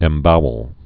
(ĕm-bouəl)